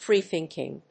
アクセント・音節frée・thínking